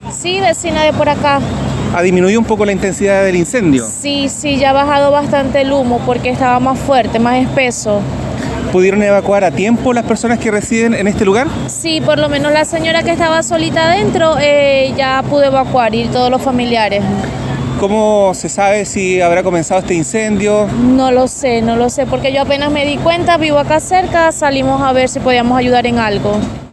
Por ahora se desconoce el origen del fuego, aunque todos los residentes pudieron evacuar rápidamente el lugar, tal como lo contó una vecina.